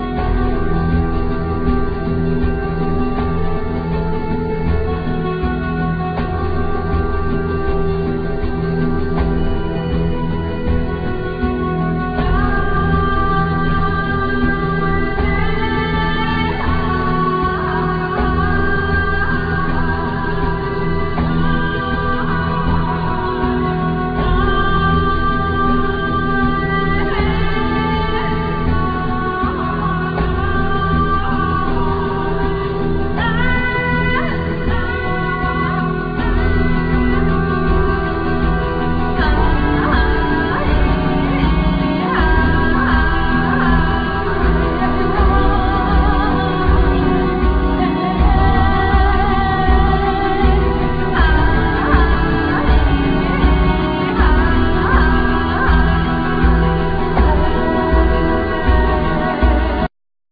Voice,Keyboards,Percussions
Keyboards,Voice,Bass,Programming
Flute
Oboe
Percussions,Ocean drums
Violin